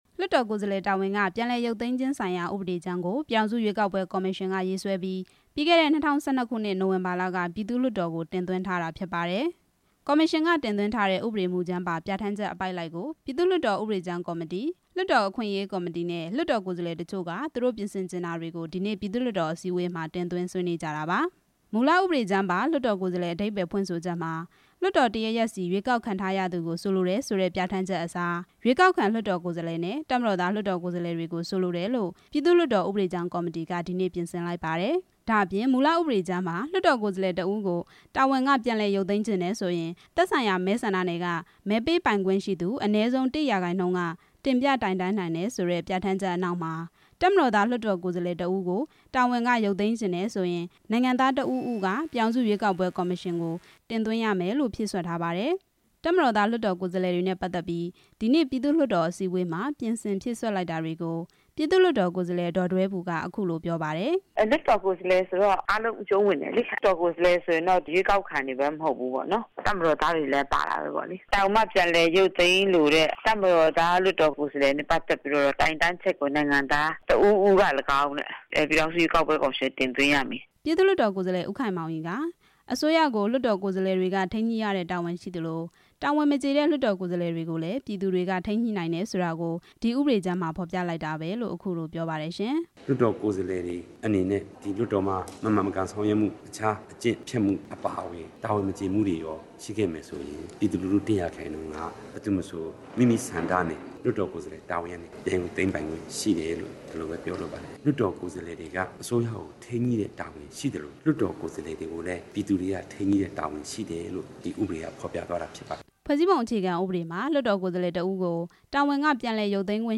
တပ်မတော်သား လွှတ်တော်ကိုယ်စားလှယ်တွေနဲ့ ပတ်သက်တဲ့အချက်တွေကို ဥပဒေကြမ်းကော်မတီ က ပြင်ဆင်လိုက်တာတွေနဲ့ ပတ်သက်လို့ ပြည်သူ့လွှတ်တော် ကိုယ်စားလှယ် ဒေါ်ဒွဲဘူက အခုလိုပြောပါတယ်။